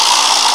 NOISE1.WAV